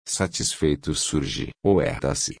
Because of this, I experimented with a more emphatic “o” by making its very own phoneme in the second example.